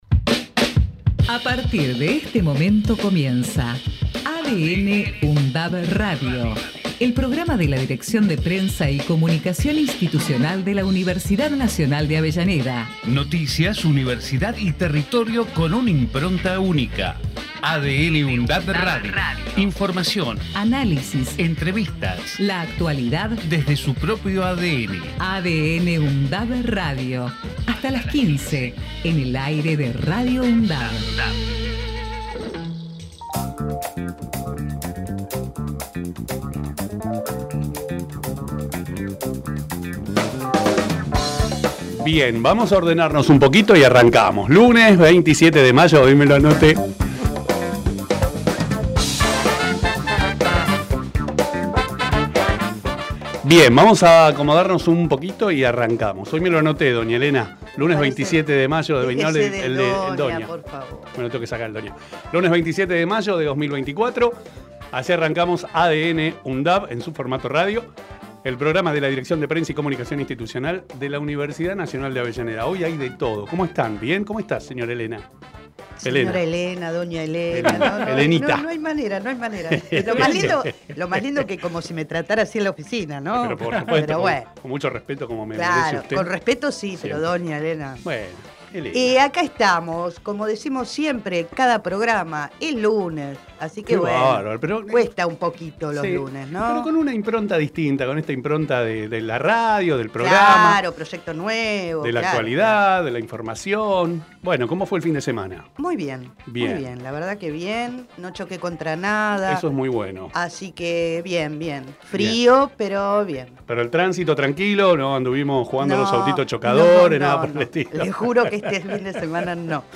ADN|UNDAV – Radio tiene la vocación de ser un aporte en tal sentido, a través de secciones como “Temas de la Uni”, “Entrevistas”, “Lo que pasa” y “En comunidad”.